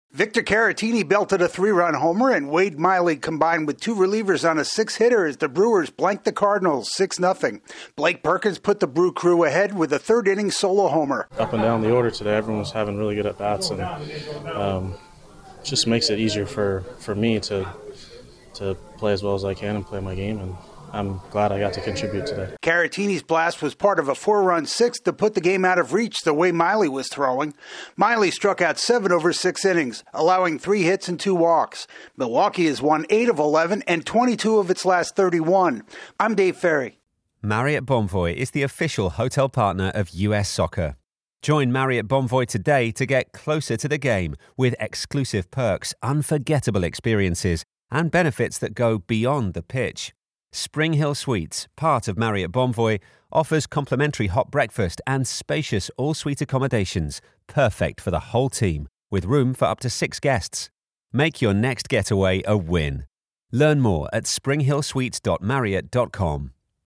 The Brewers silence the Cardinals on getaway day. AP correspondent